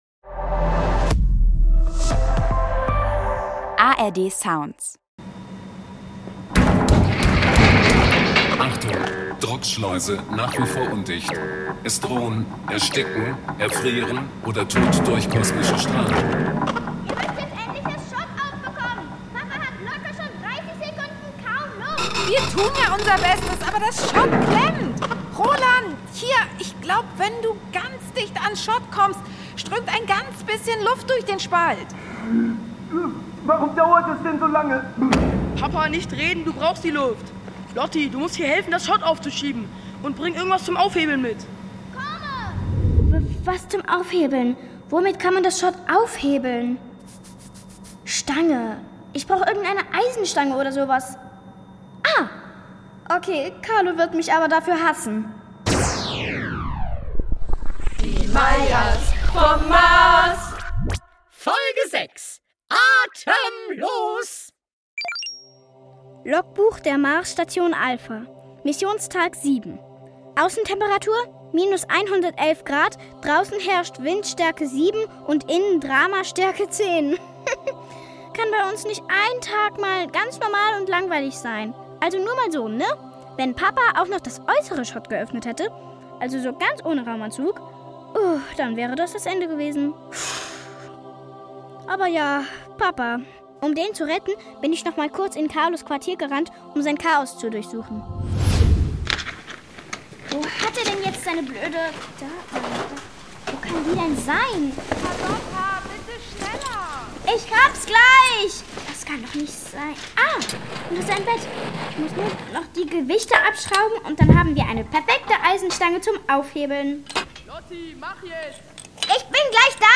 "Die Meyers vom Mars" - futuristische Familien-Comedy vom NDR